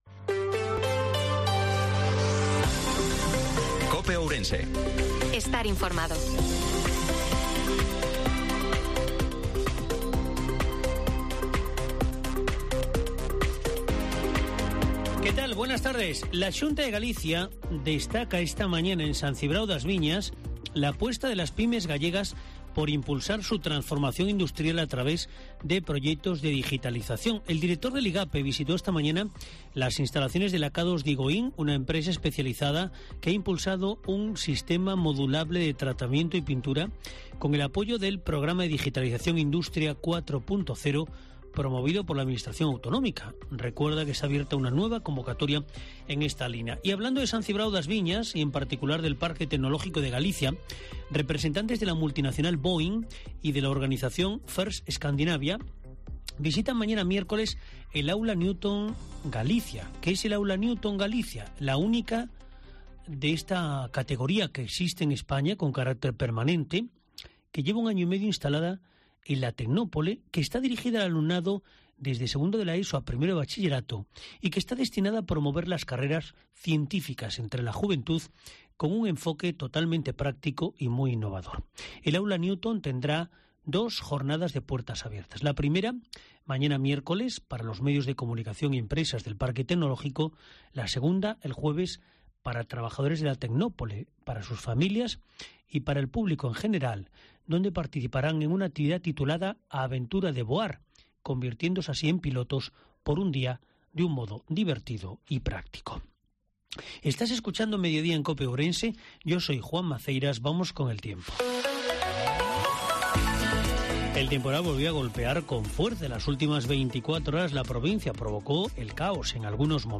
INFORMATIVO MEDIODIA COPE OURENSE-13/06/2023